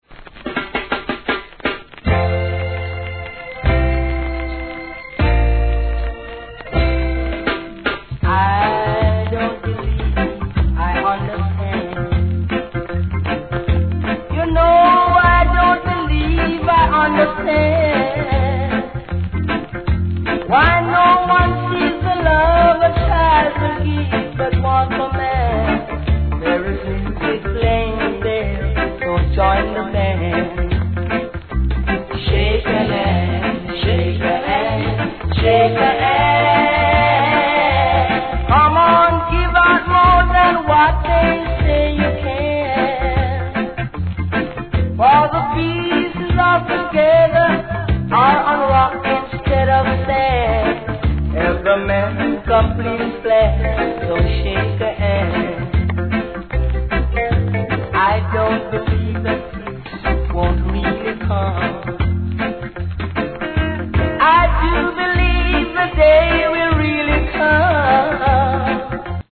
REGGAE
優しく伸びのあるヴォーカルで歌う大人気曲!!